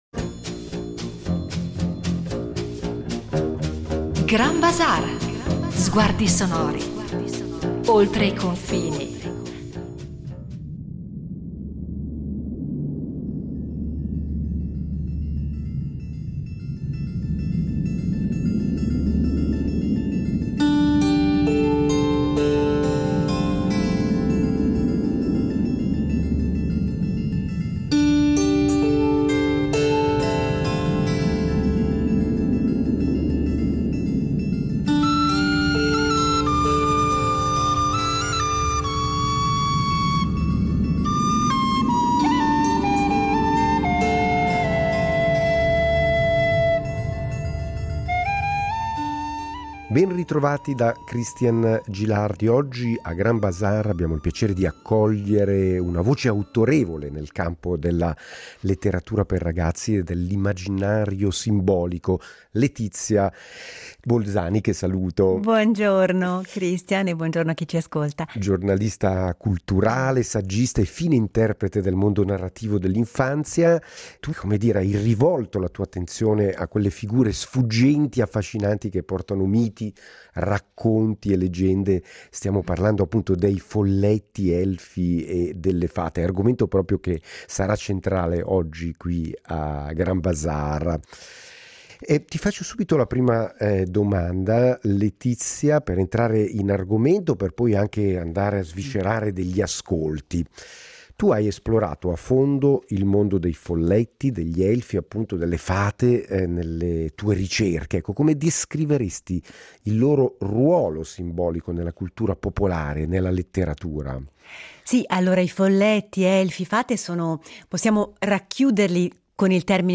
Tra la musica degli elfi, gnomi e creature che abitano l’immaginario
Insieme esploreranno i legami tra musica e immaginario fiabesco: ballate antiche, composizioni contemporanee, canti che evocano presenze sottili e misteriose, oltre a racconti, miti e leggende che da secoli dialogano con il suono.